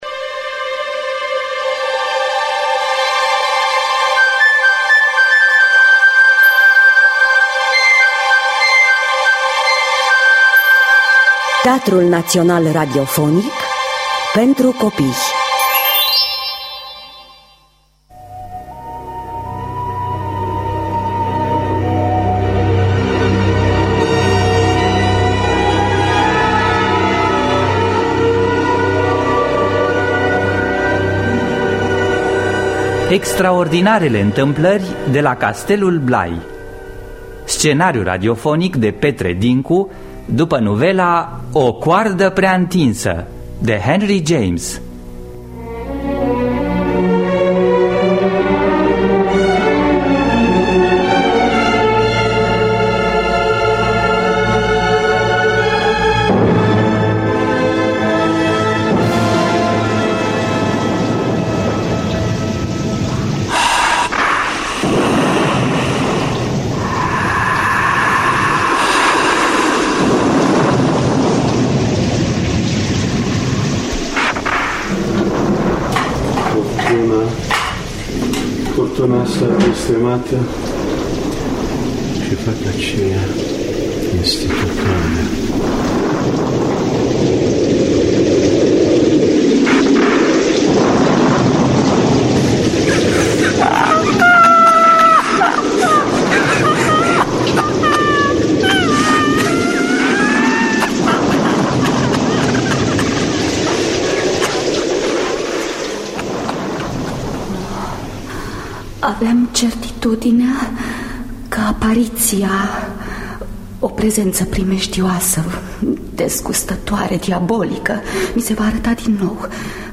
Dramatizarea radiofonică de Petre Dincu.